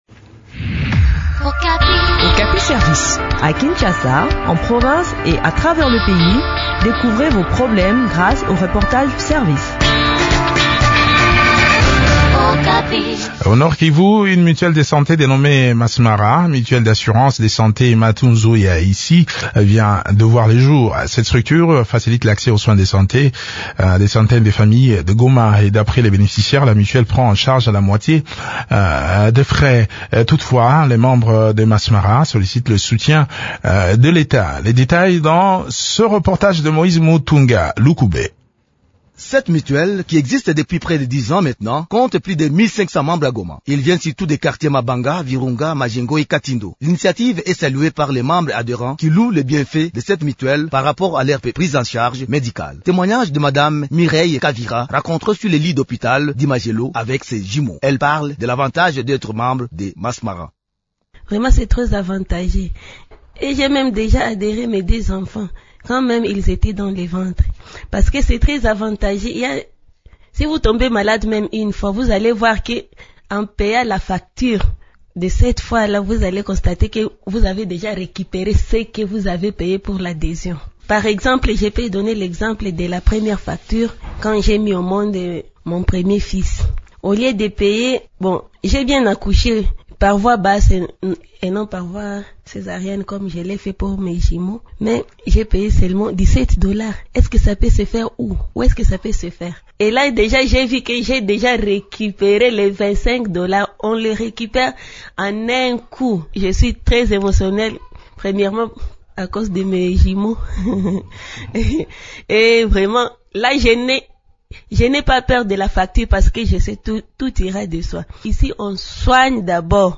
Le point sur le fonctionnement de cette mutuelle de santé dans cet entretien